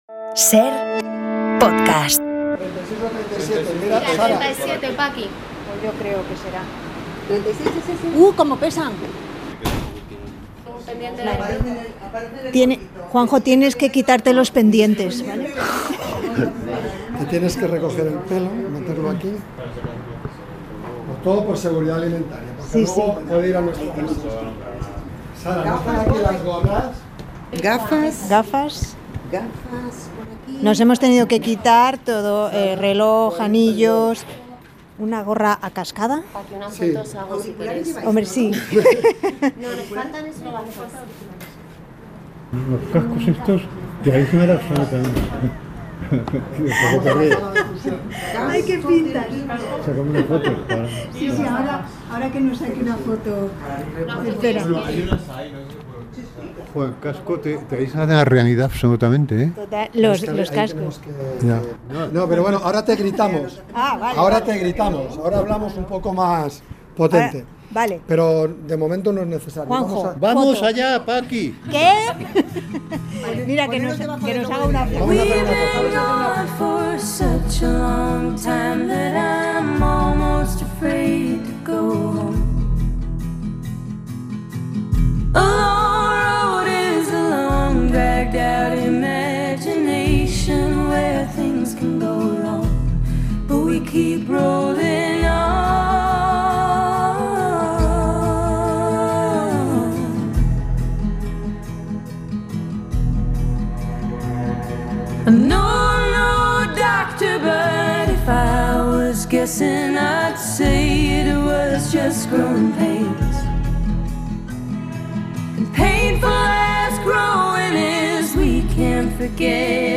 Javier del Pino y Juan José Millás conversan sobre corbatas, lipotimias y reciclaje de vidrio.